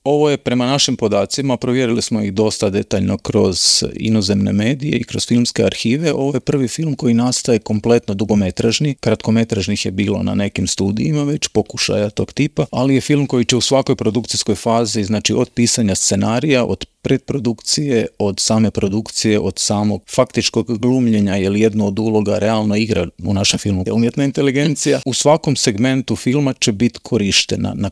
Povodom najave filma, ugostili smo ga u Intervjuu Media servisa, te ga za početak pitali u kojoj je fazi film naziva BETA.